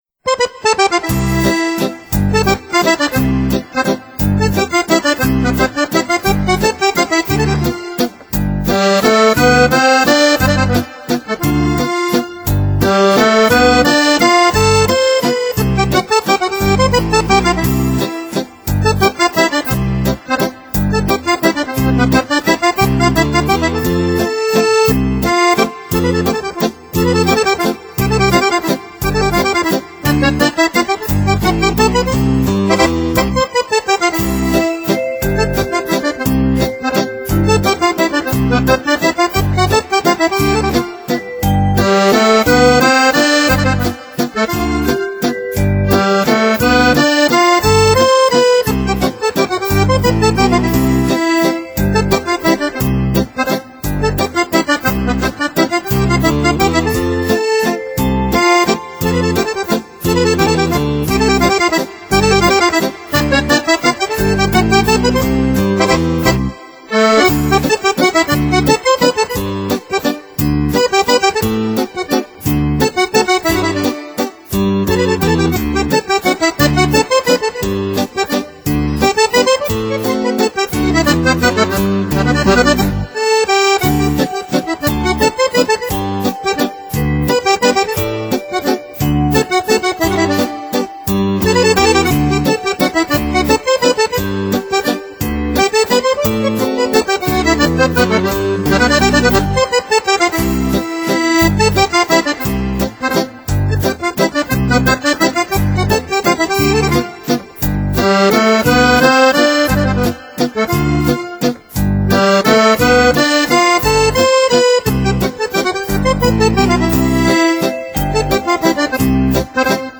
Valzer Mazurke Polke